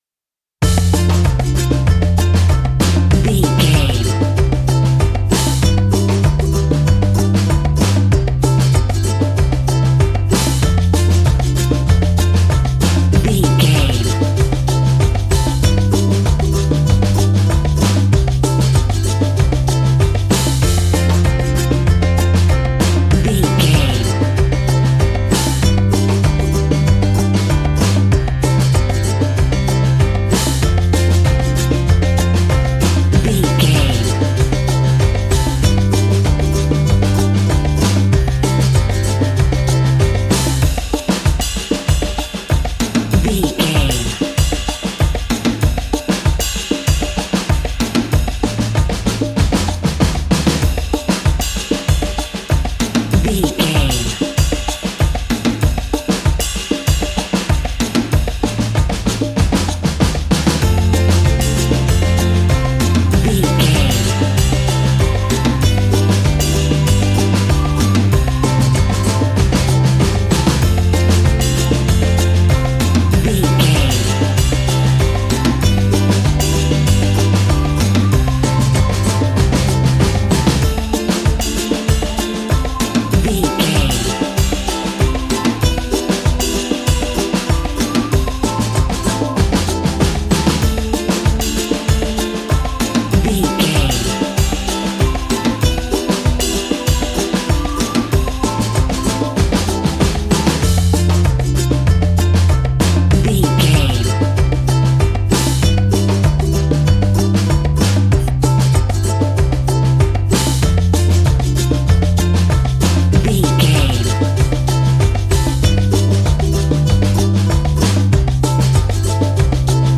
Ionian/Major
steelpan
happy
drums
percussion
bass
brass
guitar